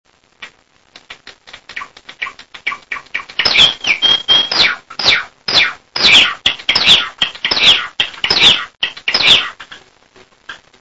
・・・ブレイ流にノロ流を加味したデコのさえずり